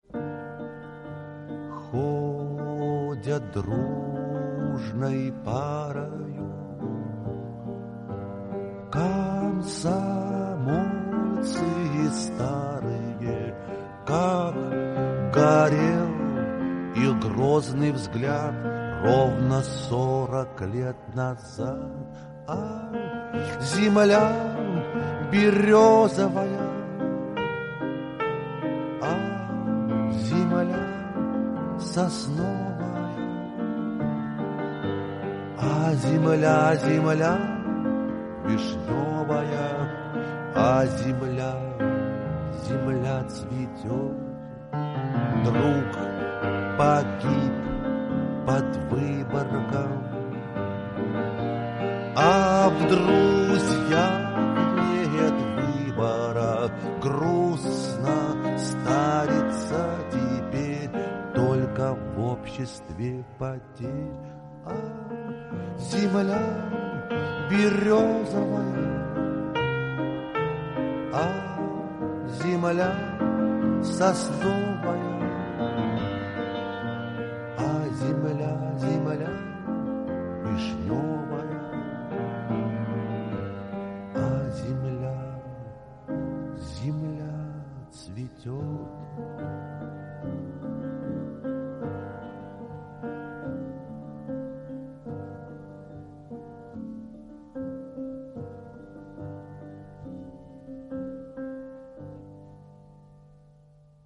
Исполняет автор